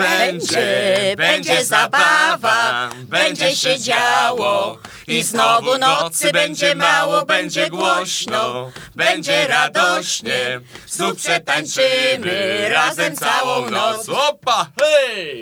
A tak zespół śpiewał na 90.3 FM.
Tworzą ją rezolutne mieszkanki gminy Dolice i dziarscy muzycy.
akordeonie